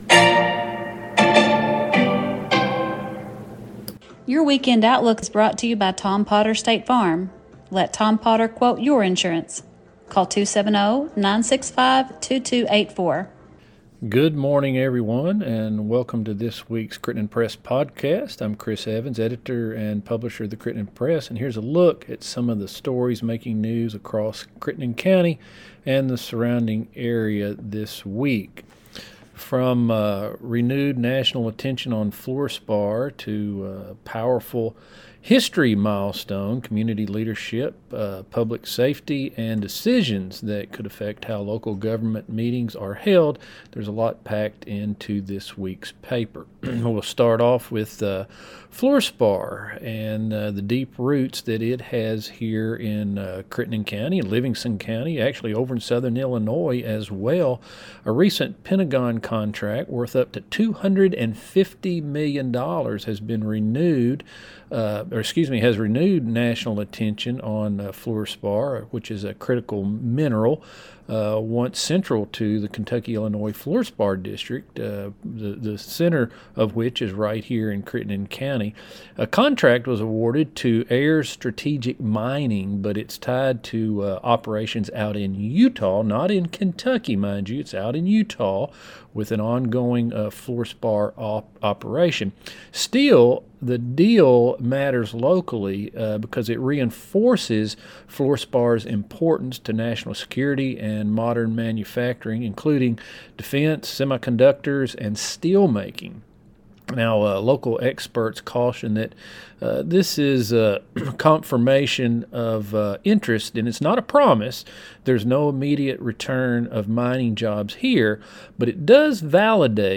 Newscast